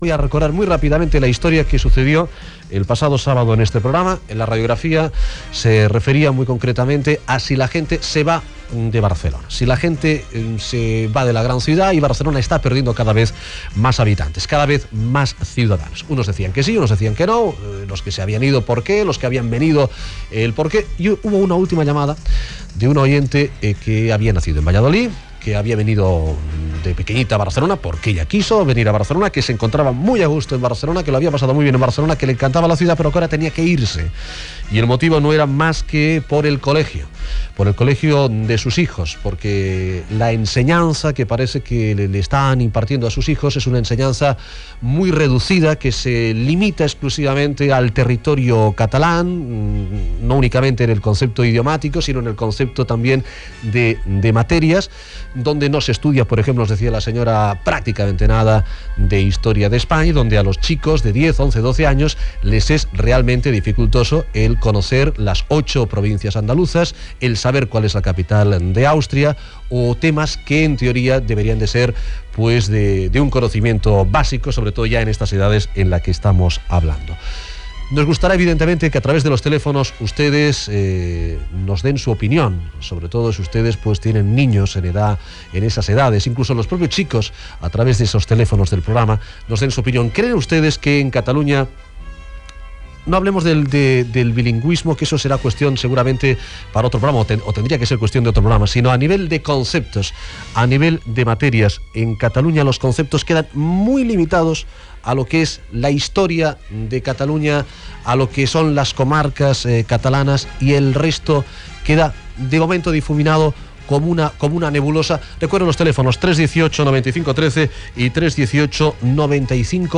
les opinions telefòniques de diversos oïdors Gènere radiofònic Divulgació